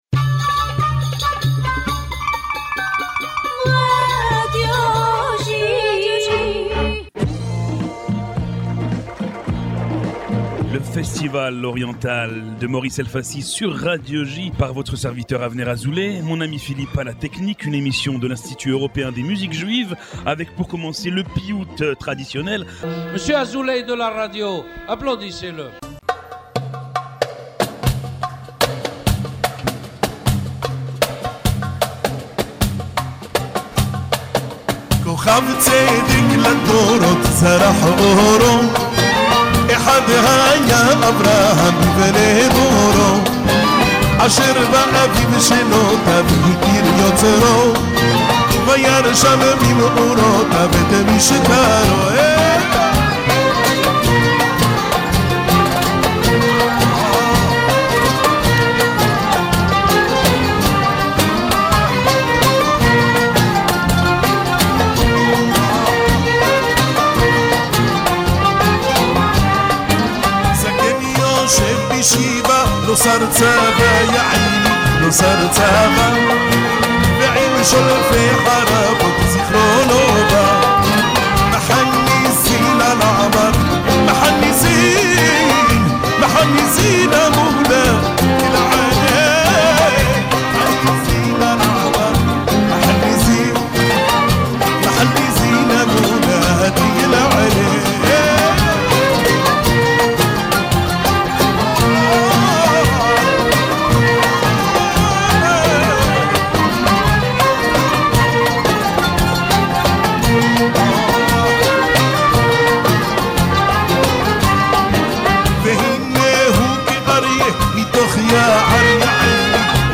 Broadcasted every Monday on Radio J (94.8 FM), « The Oriental festival » is a radio program from the European Institute of Jewish Music entirely dedicated to Eastern Music.